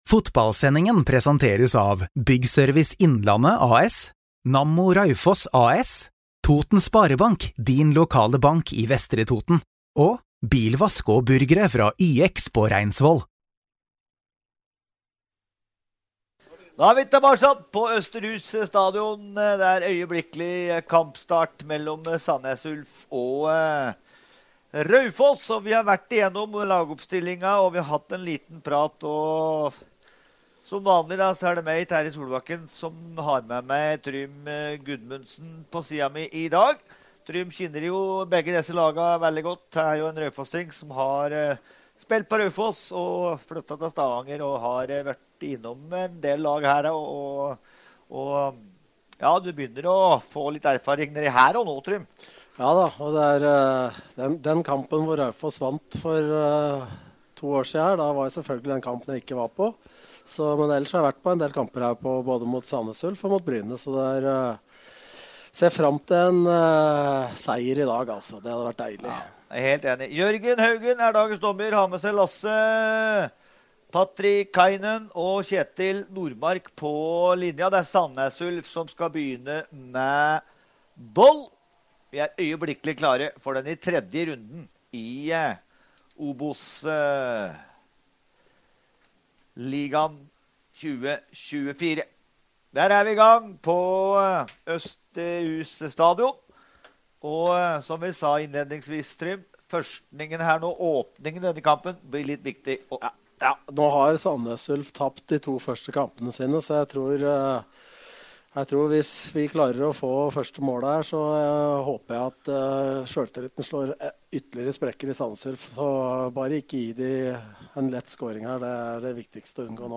Hele kampen kan du høre her: SANDNES ULF –RAUFOSS